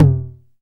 Index of /90_sSampleCDs/Roland - Rhythm Section/DRM_Drum Machine/KIT_TR-909 Kit
TOM 909 TO04.wav